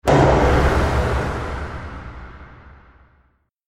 جلوه های صوتی
دانلود صدای بمب 6 از ساعد نیوز با لینک مستقیم و کیفیت بالا